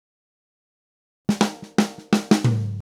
Drumset Fill 11.wav